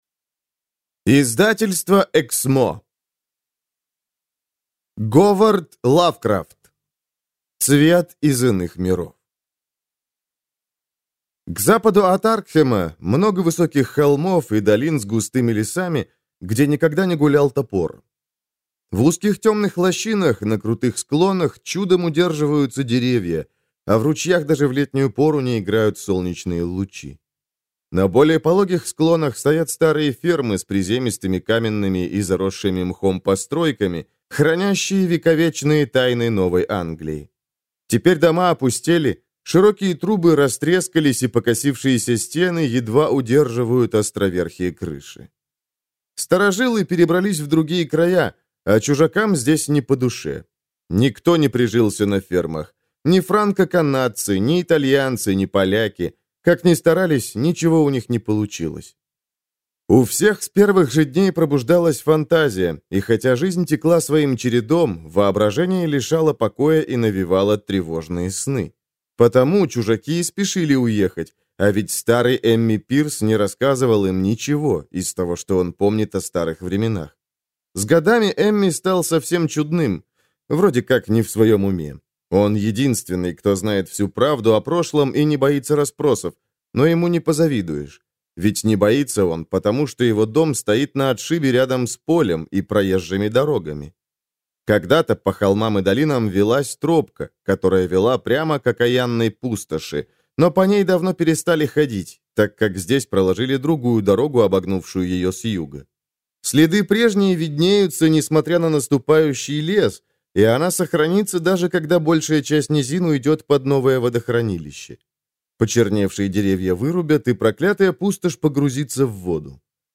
Аудиокнига Цвет из иных миров | Библиотека аудиокниг
Прослушать и бесплатно скачать фрагмент аудиокниги